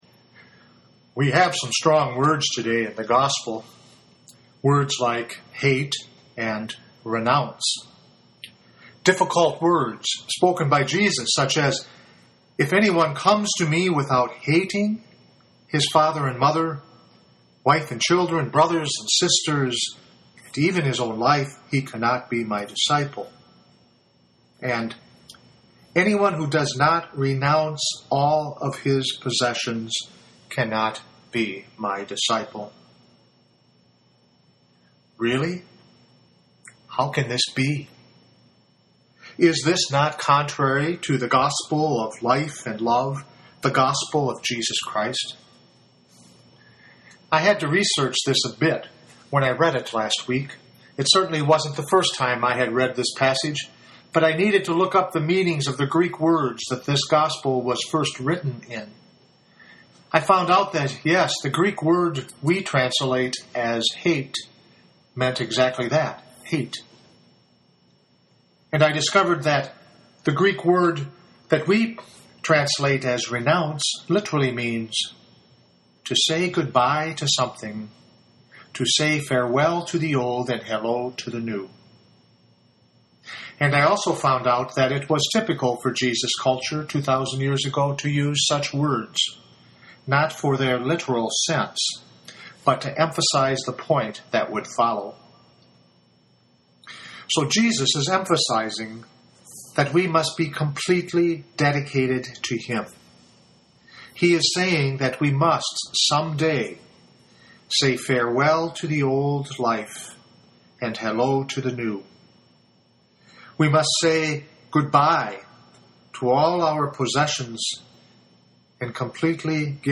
Here is my homily for this weekend.